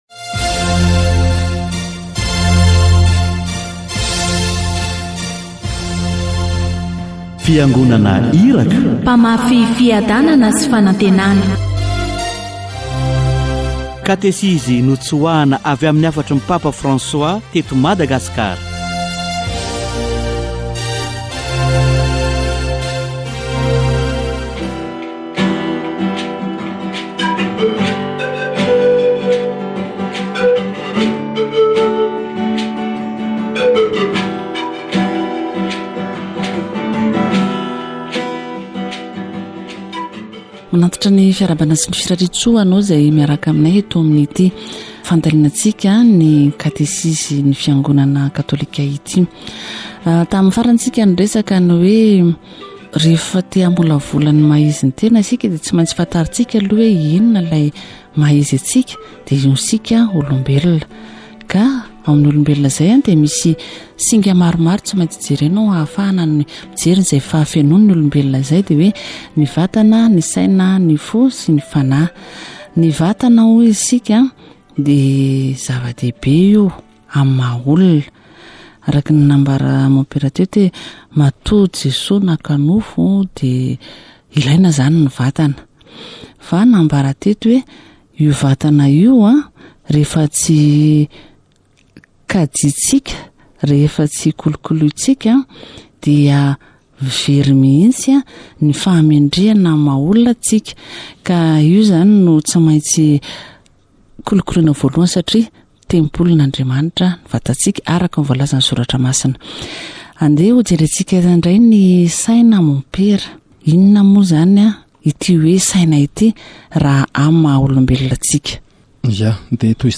La perspicacité, le savoir-vivre et le savoir-faire sont inséparables. Catéchèse sur la formation du soi